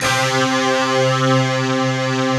Index of /90_sSampleCDs/Optical Media International - Sonic Images Library/SI1_DistortGuitr/SI1_200 GTR`s